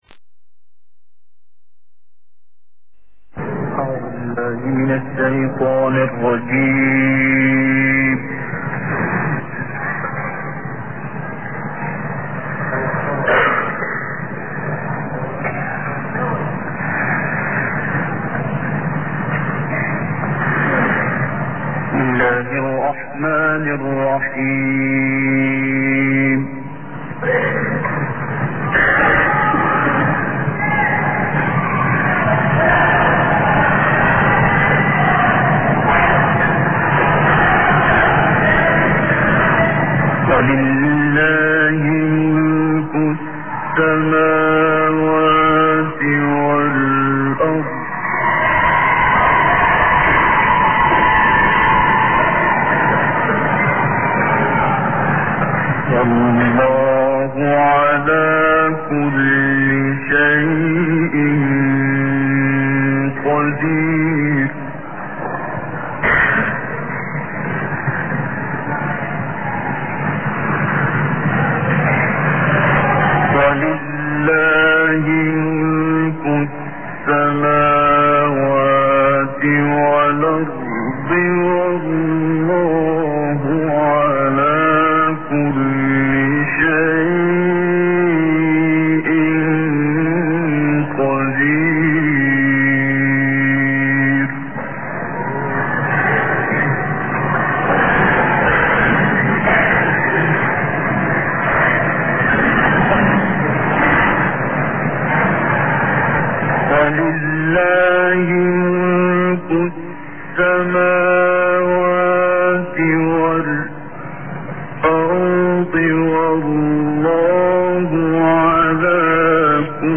حفلات نادرة جدا للشيخ عبد الباسط عبد الصمد النادرة فى سوريا